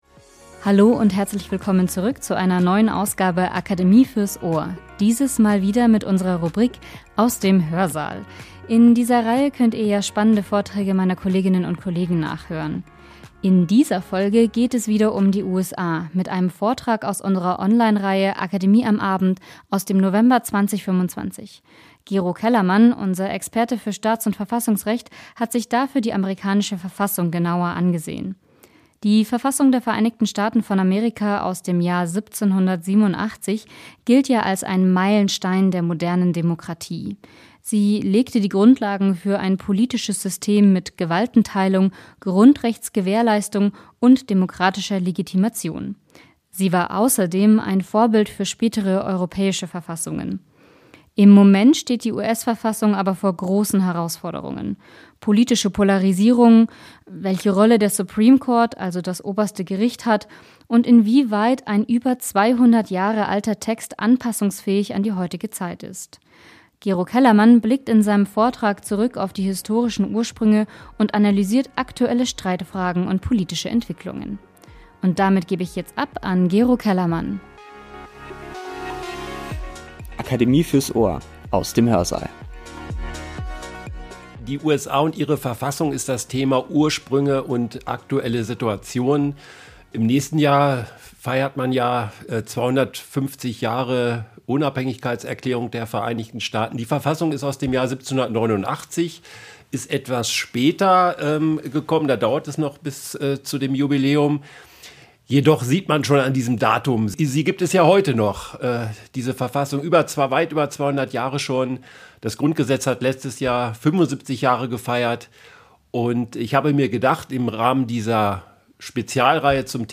In unserer Podcast-Rubrik "Aus dem Hörsaal" gibt es spannende Vorträge des Akademie-Kollegiums zum Nachhören.